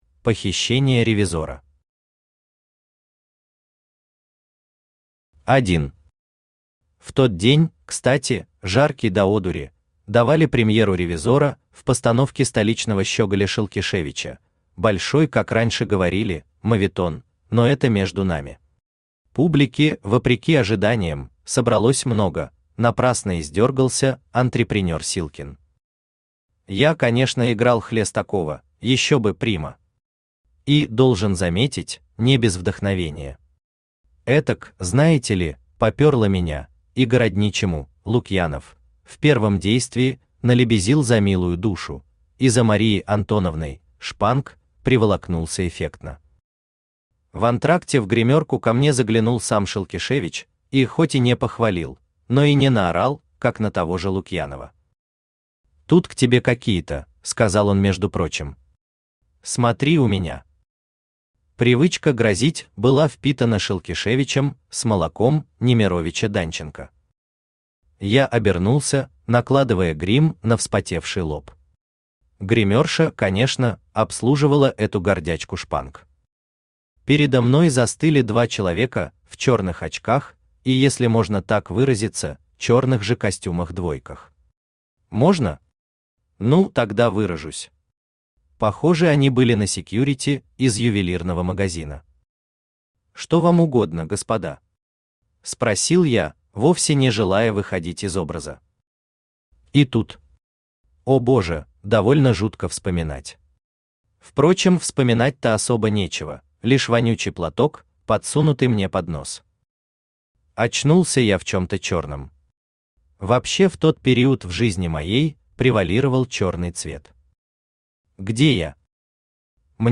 Аудиокнига Лицедей | Библиотека аудиокниг
Aудиокнига Лицедей Автор Василий Дмитриевич Гавриленко Читает аудиокнигу Авточтец ЛитРес.